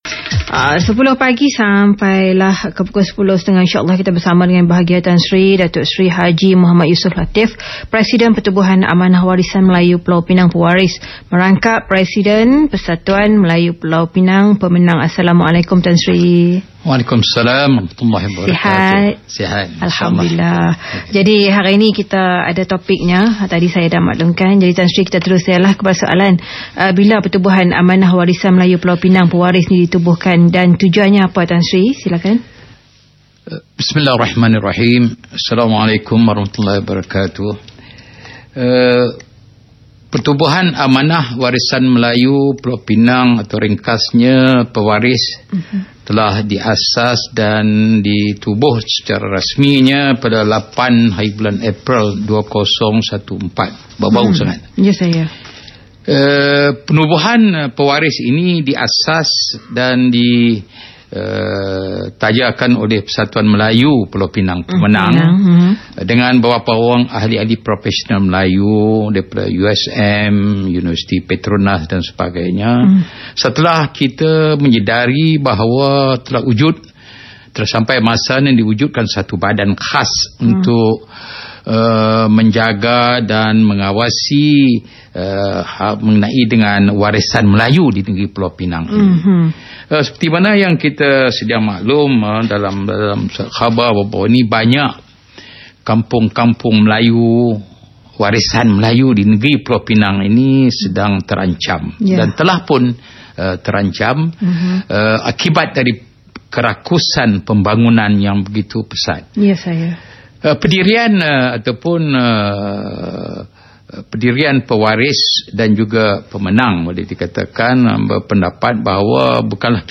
Rakaman Siaran Mutiara FM | Temuramah bersama Y. Bhg. Tan Sri Dato’ Seri Haji Mohd Yussof Latiff